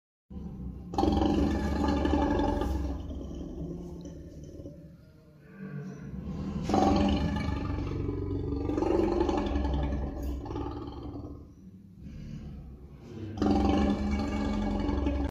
Lion's Voice 🦁🔊